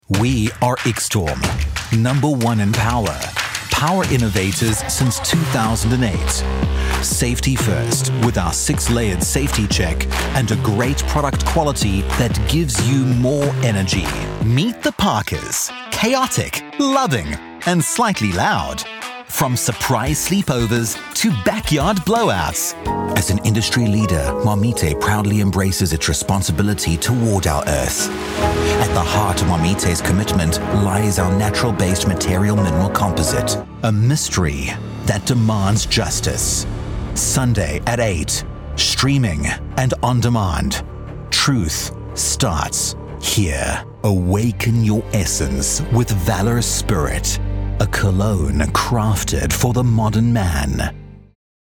Warm, sanft und vielseitig. Erfahren und einnehmend.
Klar
Prägnant
Warm